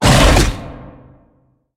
Sfx_creature_bruteshark_chase_os_02.ogg